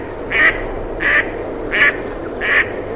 Germano reale – Màzaro
Anas platyrhynchos
Le femmina produce un profondo ‘qua, qua’; il maschio emette un più sommesso e acuto ‘quek’ o ‘reeb’.
Germano_Reale_Anas_platyrhynchos.mp3